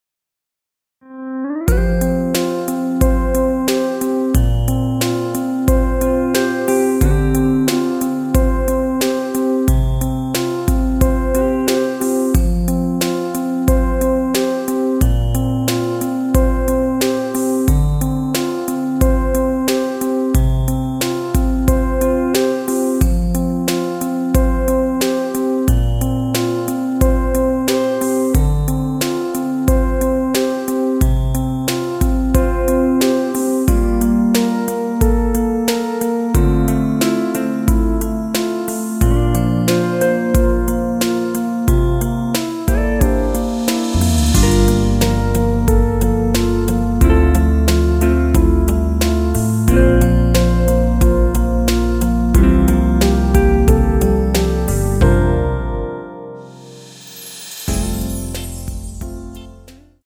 ◈ 곡명 옆 (-1)은 반음 내림, (+1)은 반음 올림 입니다.
앞부분30초, 뒷부분30초씩 편집해서 올려 드리고 있습니다.
중간에 음이 끈어지고 다시 나오는 이유는